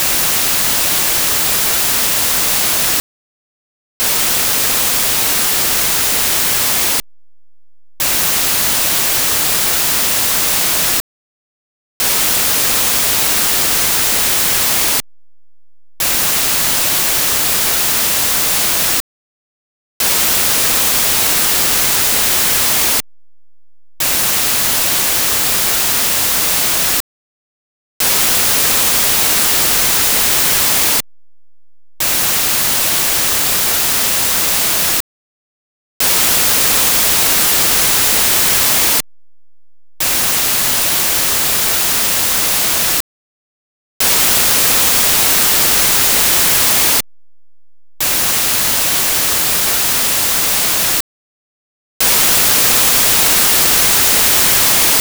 RUIDO BLANCO
En el ruido blanco, como en la luz blanca, todas las frecuencias tienen una representación equivalente, es decir, se compone de todas las frecuencias audibles a la misma amplitud.
Un ejemplo de ruido blanco es el producido por el televisor cuando se corta la recepción.